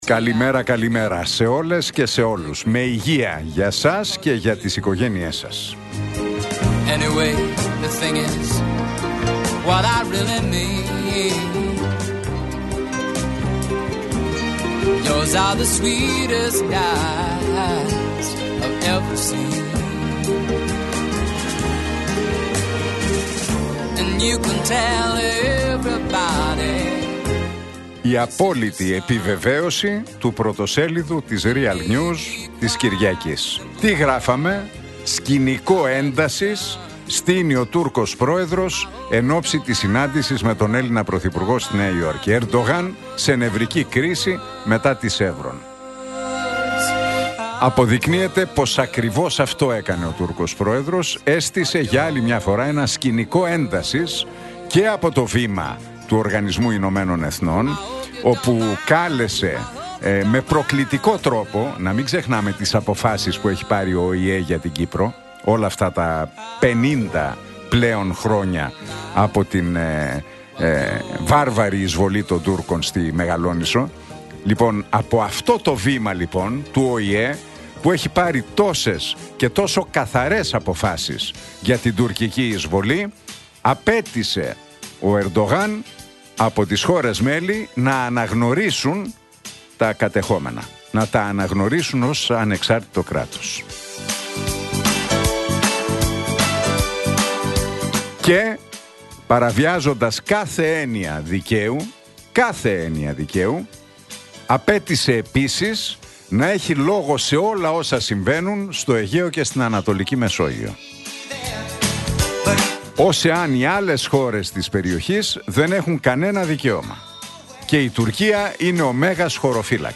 Ακούστε το σχόλιο του Νίκου Χατζηνικολάου στον ραδιοφωνικό σταθμό Realfm 97,8, την Τετάρτη 24 Σεπτεμβρίου 2025.